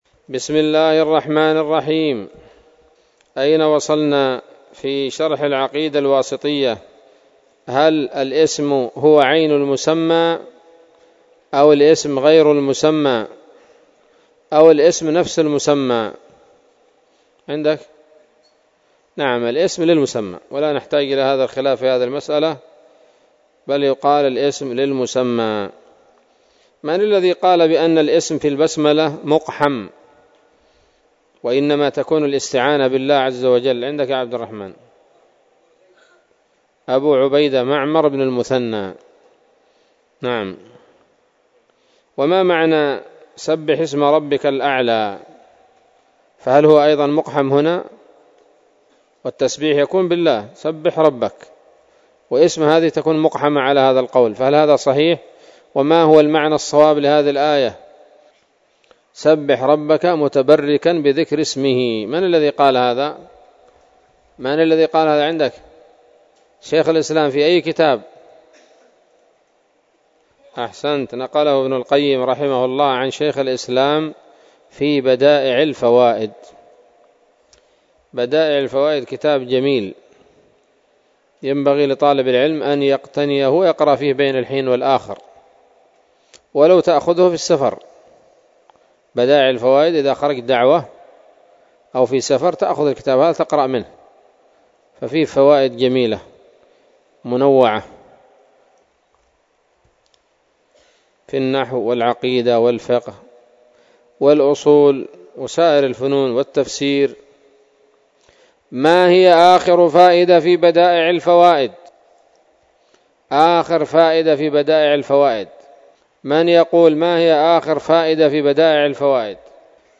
الدرس الخامس من شرح العقيدة الواسطية للهراس